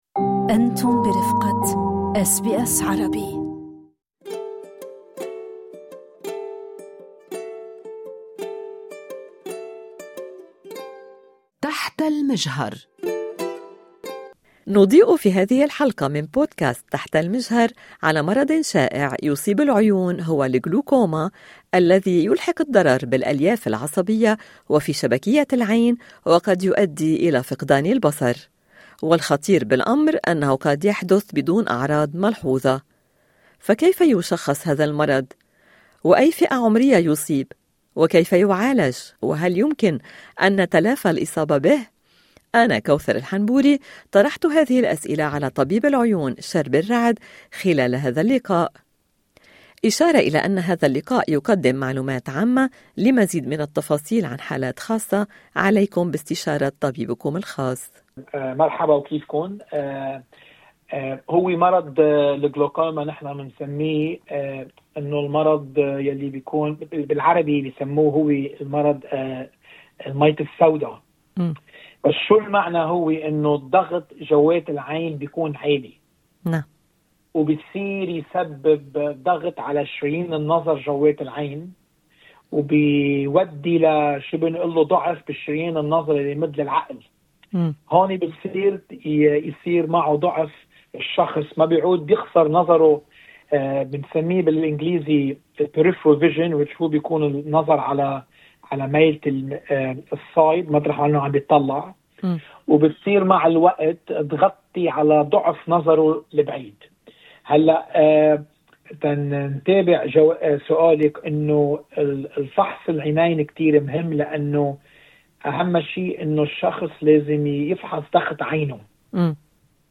حملنا هذه الأسئلة الى طبيب العيون